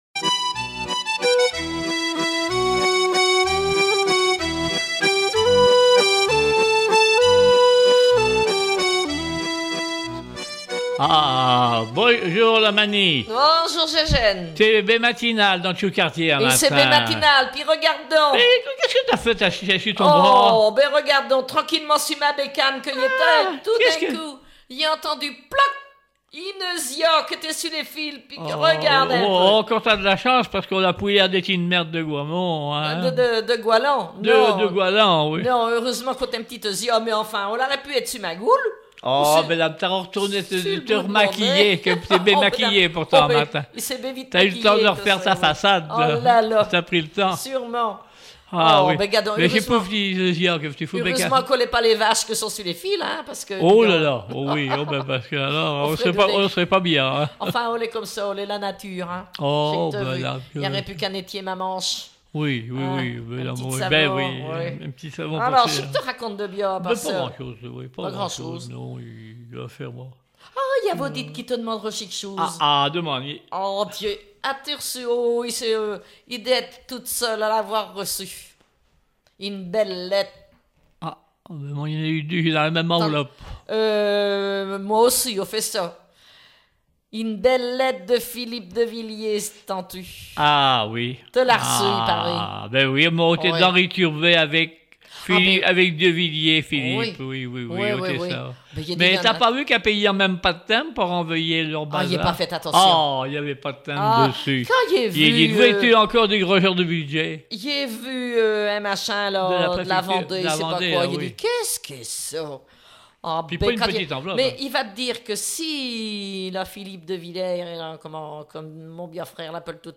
Patois local
Témoignage